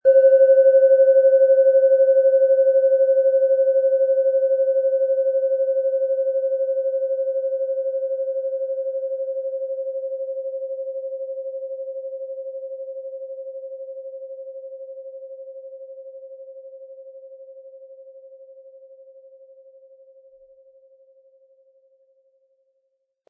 OM Ton
Handgearbeitete tibetische Schale mit dem Planetenton OM-Ton.
Unter dem Artikel-Bild finden Sie den Original-Klang dieser Schale im Audio-Player - Jetzt reinhören.
MaterialBronze